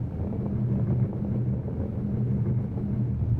bowling_ball_roll_loop.wav